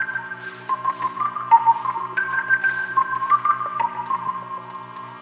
Back Alley Cat (Organ 04).wav